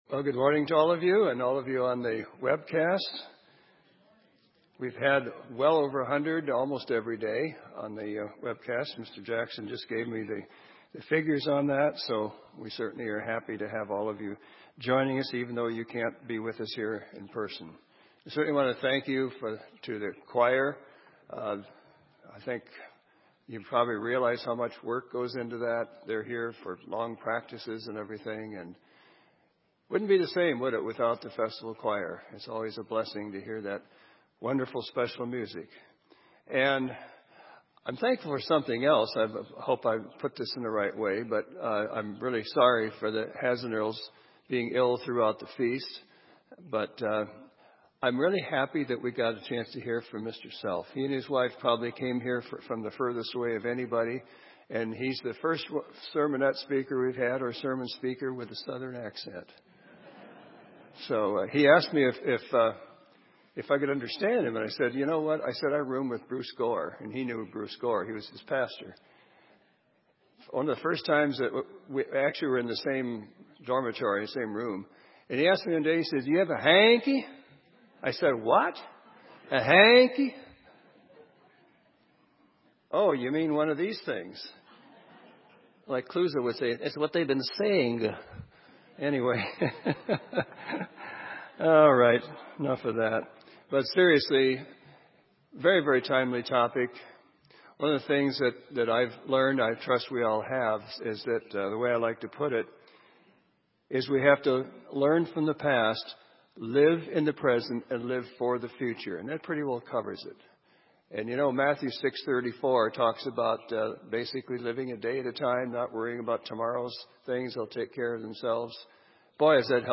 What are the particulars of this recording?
This sermon was given at the Bend, Oregon 2016 Feast site.